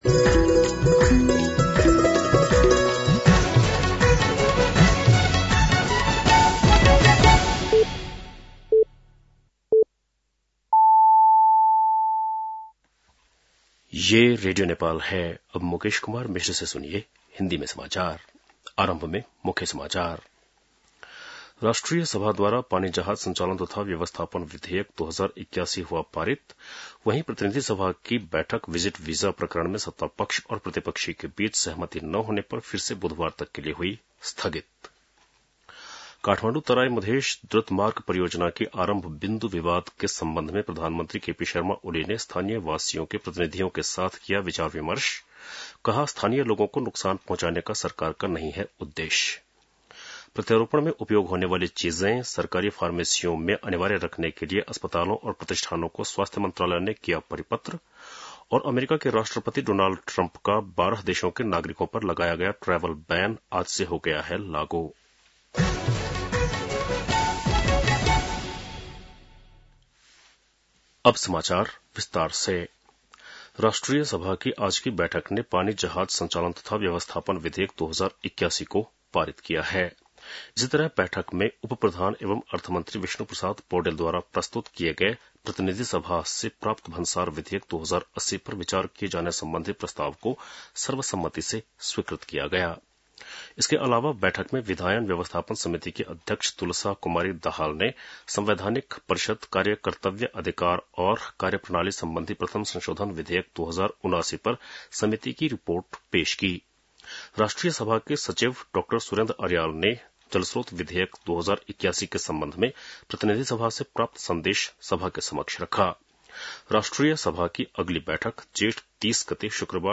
बेलुकी १० बजेको हिन्दी समाचार : २६ जेठ , २०८२
10-PM-Hindi-NEWS.mp3